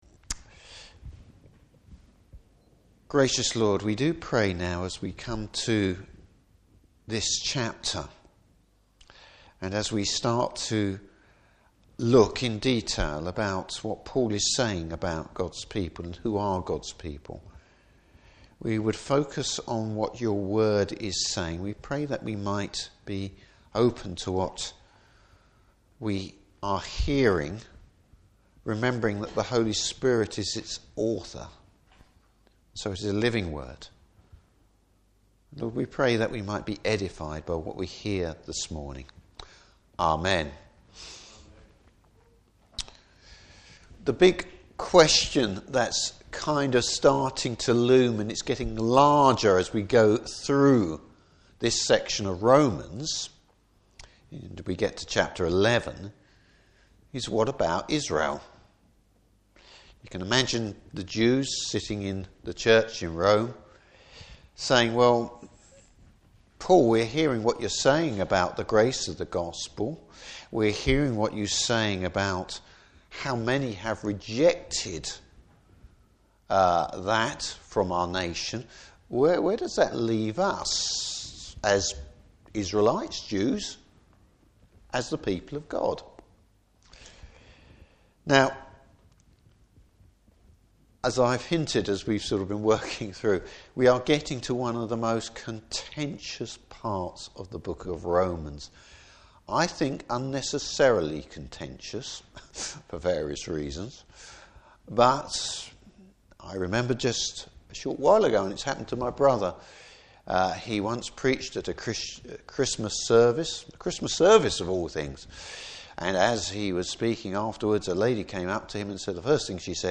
Service Type: Morning Service Has the Lord rejected Israel?